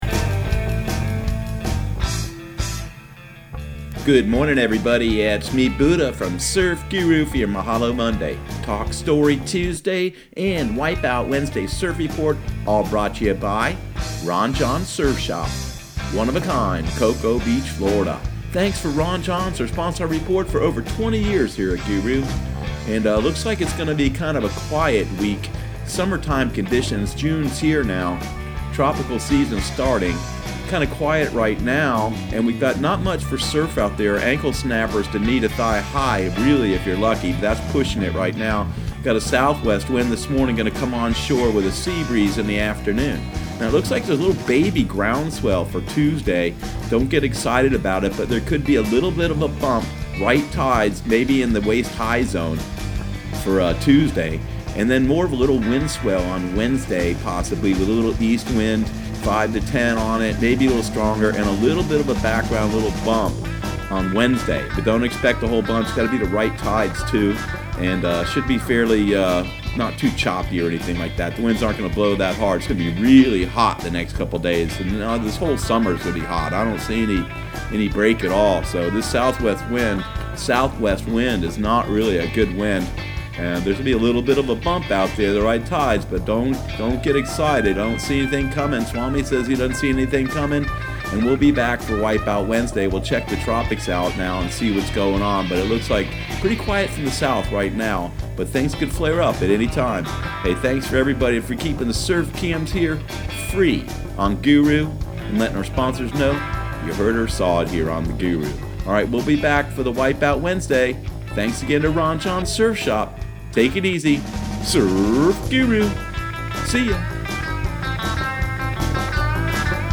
Surf Guru Surf Report and Forecast 06/03/2019 Audio surf report and surf forecast on June 03 for Central Florida and the Southeast.